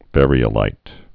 (vârē-ə-līt, văr-)